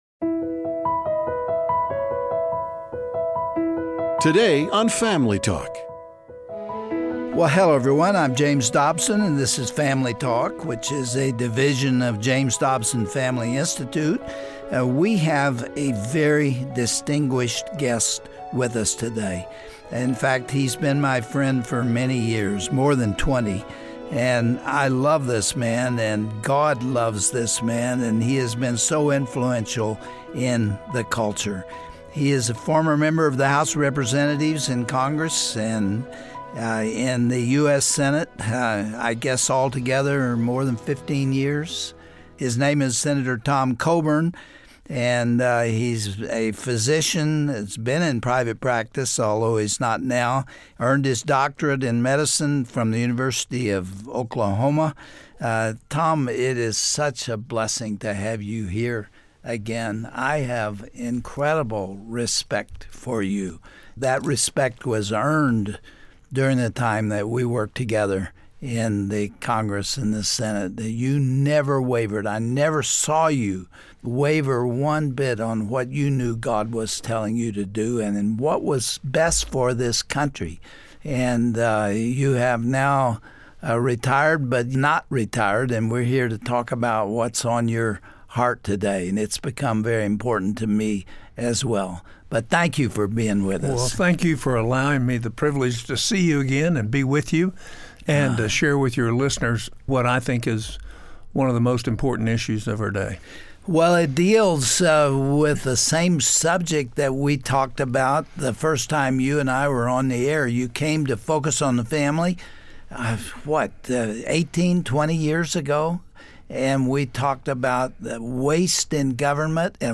Dr. Dobson talks about a real solution to these growing issues with former Senator Tom Coburn. He addresses the fiscal irresponsibility of Washington, and why a Convention of States needs to be called.
Host Dr. James Dobson Guest(s) Guest(s):Sen.